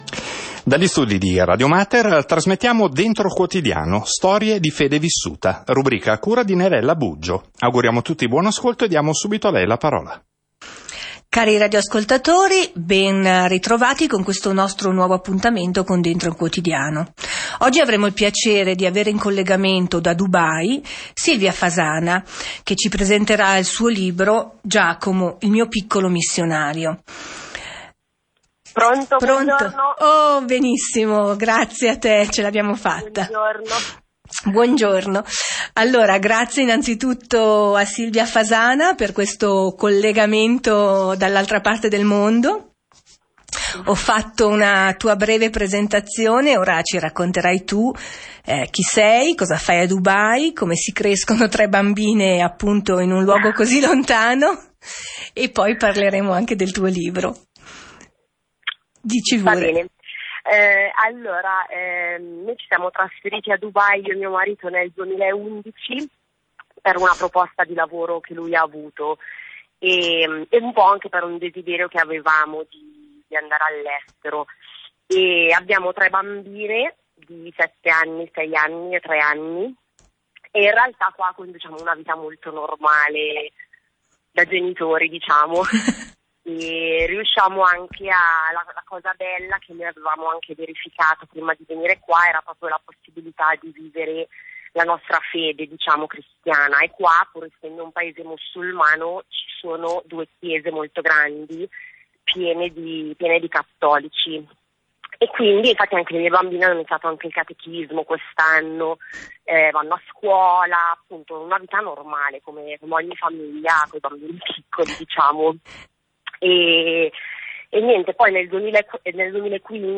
L’intervista di Radio Mater